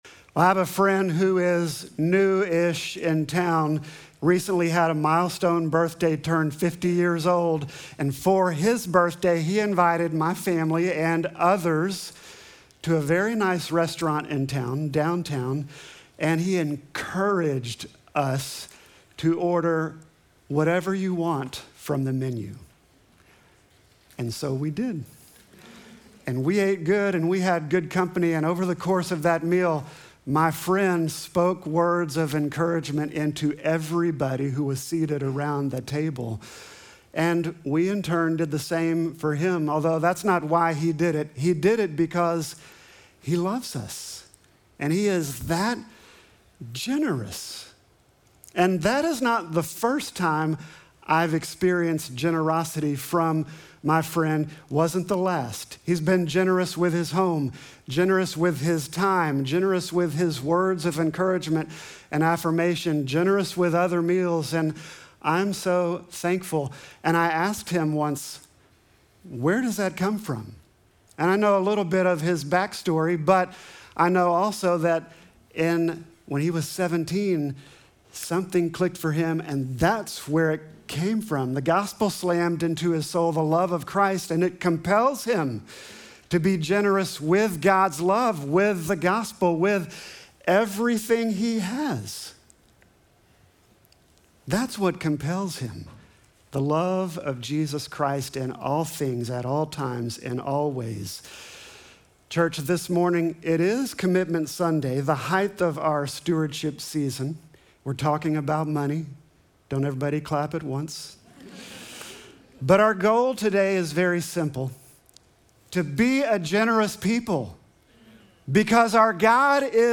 Sermon text: 2 Corinthians 9:6-8